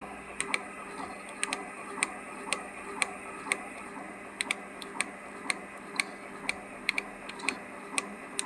It makes a strange clicking sound when scanning and at that moment the image skips...